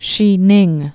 (shēnĭng)